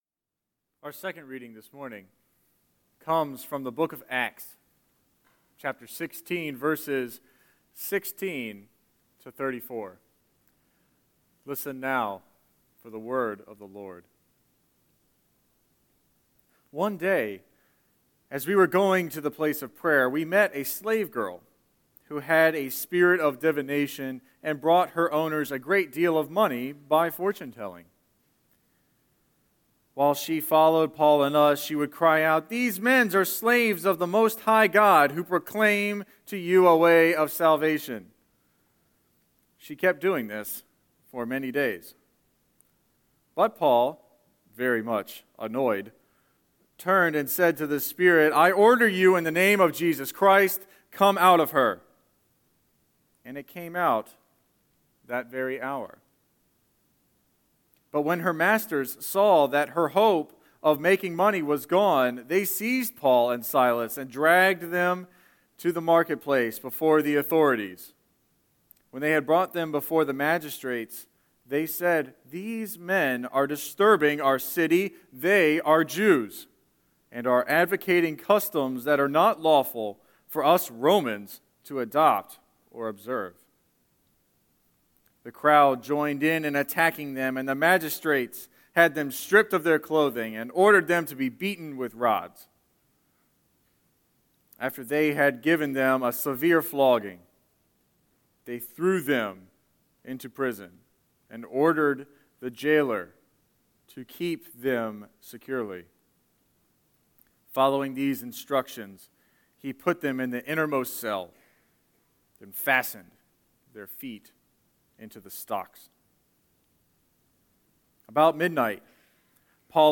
05-08-Scripture-and-Sermon.mp3